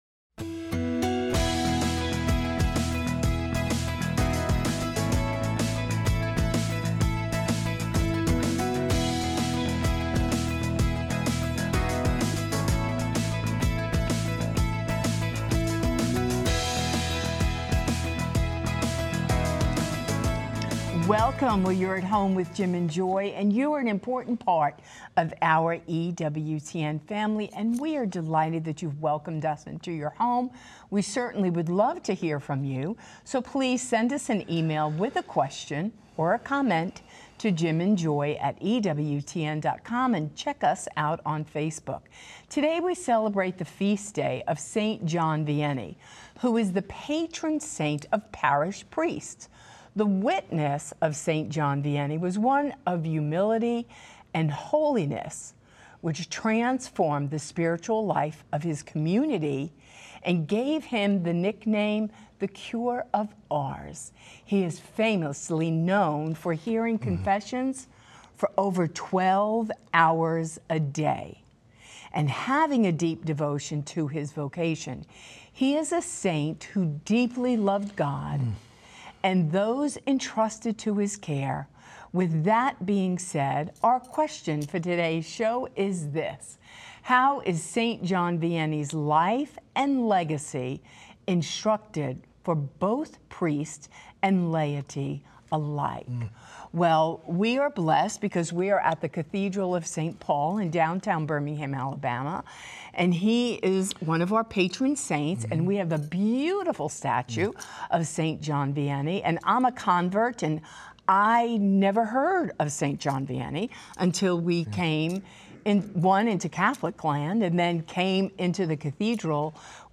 Call-in Show